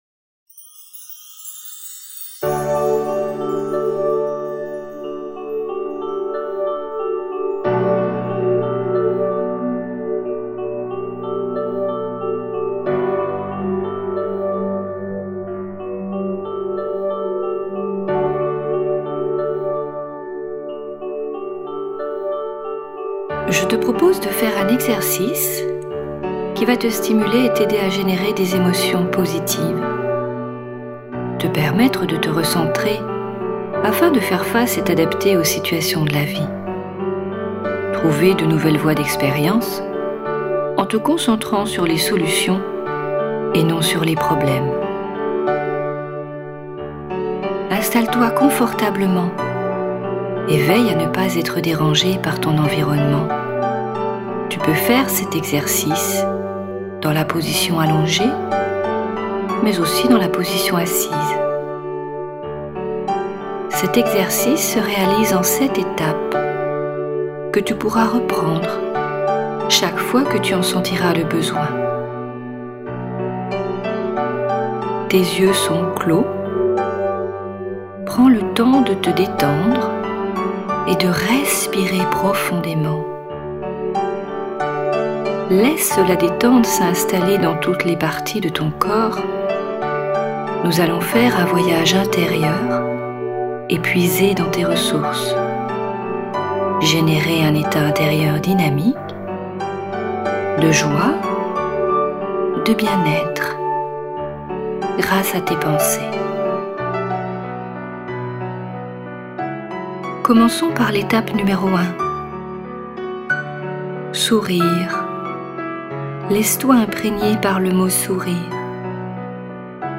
L’harmonie de la musique associée à la voix vous permettra de vivre 3 méditations très différentes.